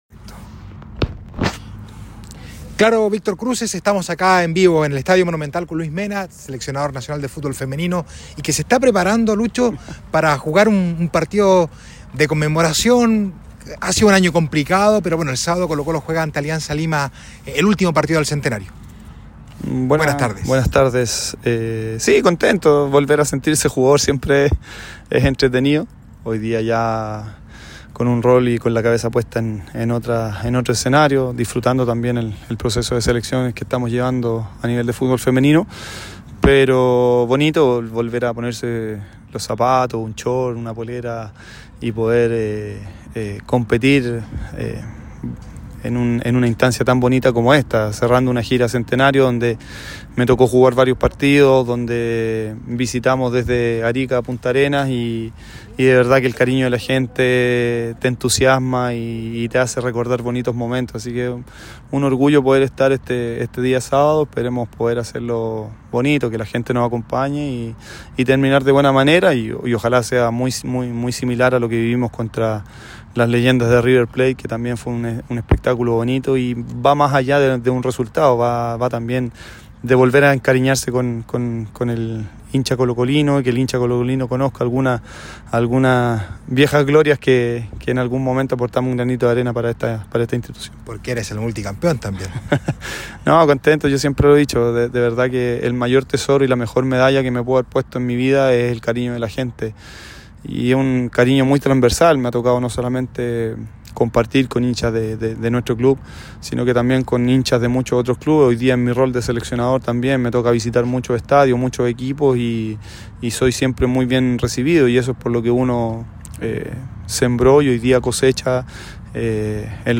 en conversación con ADN Deportes